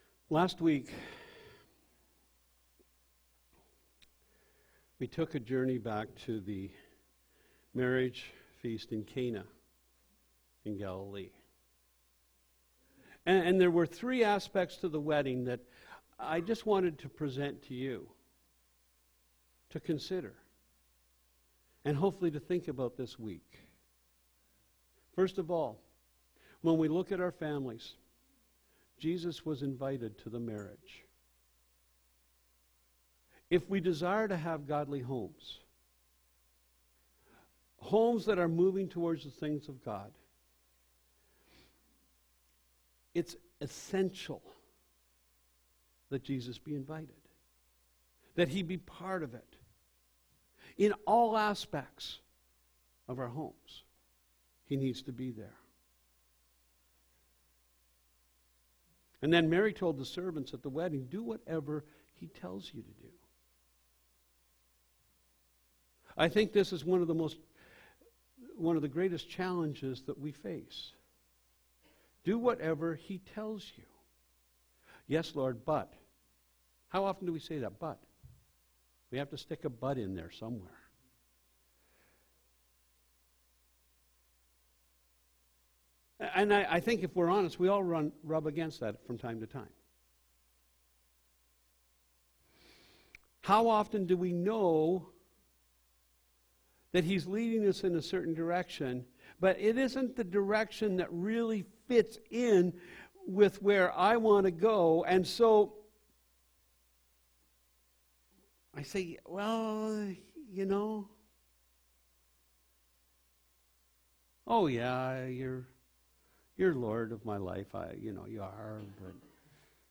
Sermons | Peace River Alliance Church